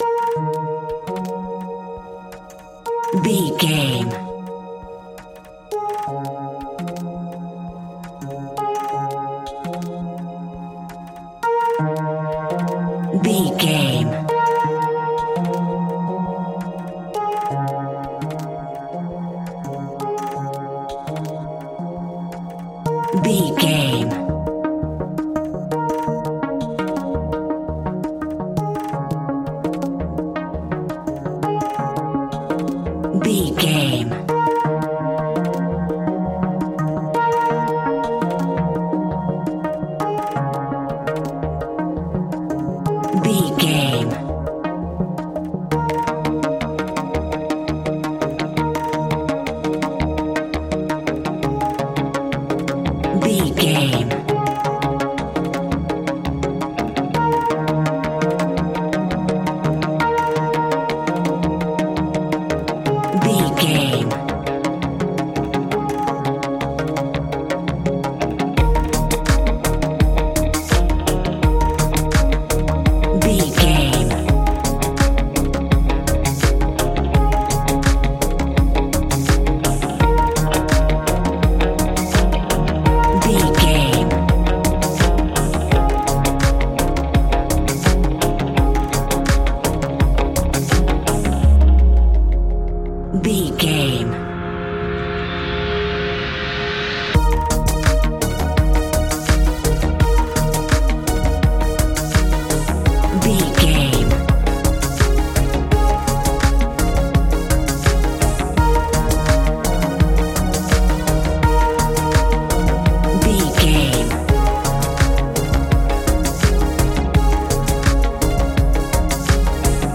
Ionian/Major
D♯
electronic
techno
trance
synths
synthwave
instrumentals